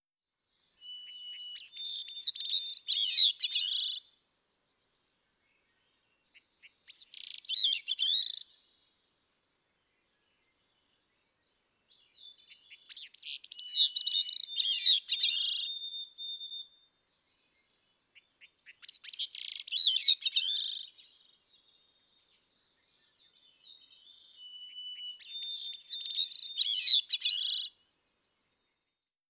ForrestBird4.wav